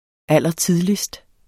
Udtale [ ˈalˀʌˈtiðlisd ]